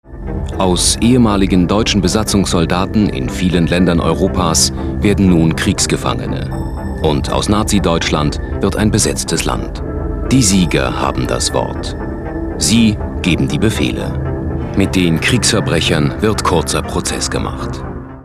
deutscher Sprecher. freiberuflicher Sprecher für ARD, ARTE, KIKA usw.
Sprechprobe: Industrie (Muttersprache):